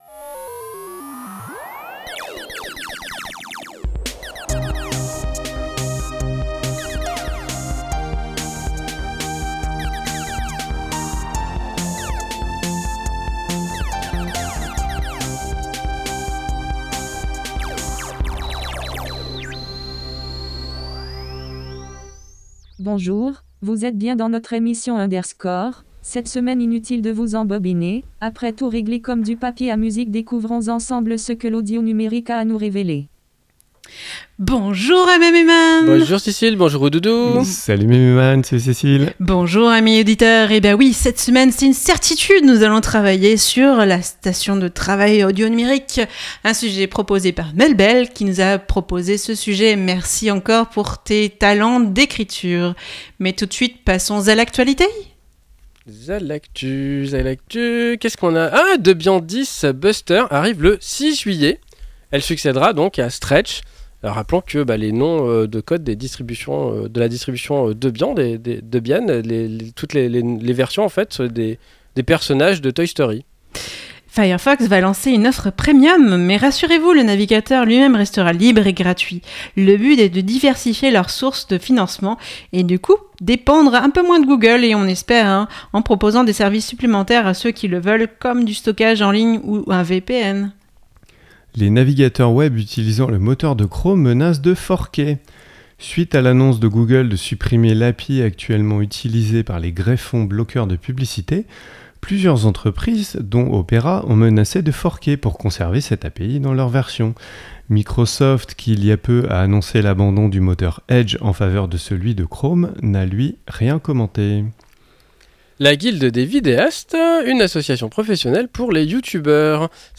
La station de travail audio-numérique De l'actu, une pause chiptune, un sujet, l'agenda, et astrologeek !